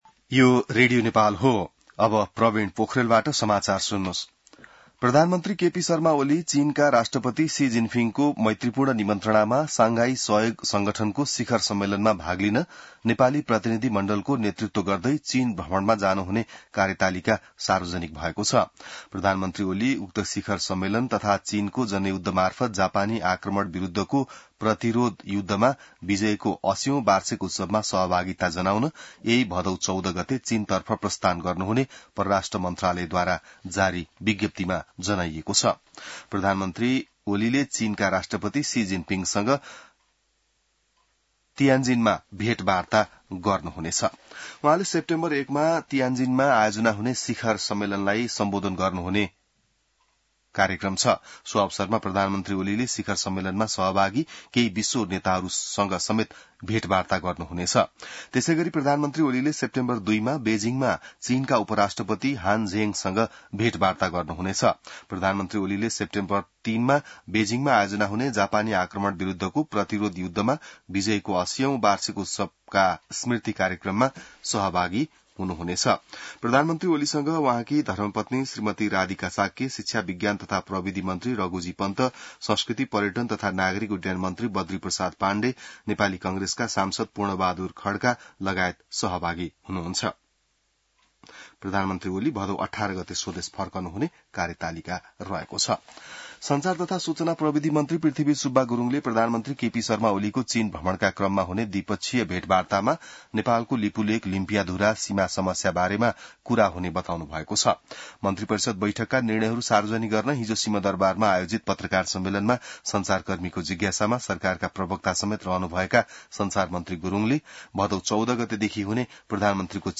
An online outlet of Nepal's national radio broadcaster
बिहान ६ बजेको नेपाली समाचार : १२ भदौ , २०८२